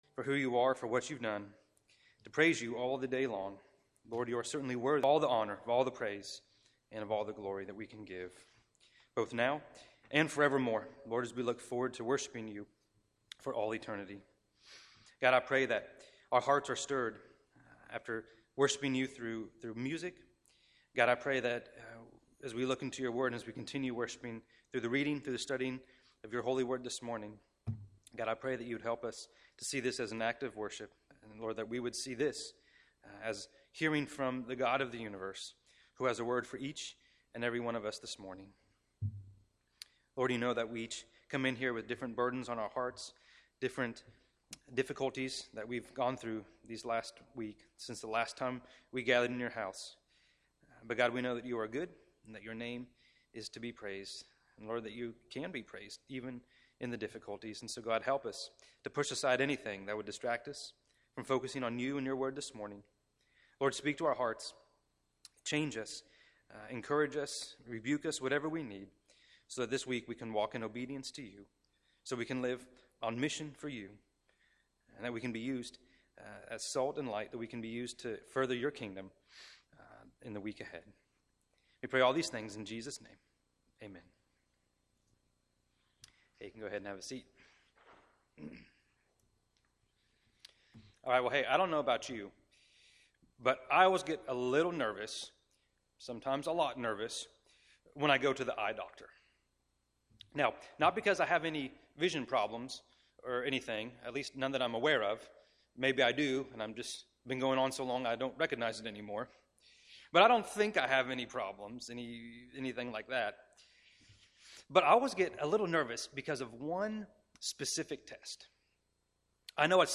A message from the series "English Sermons."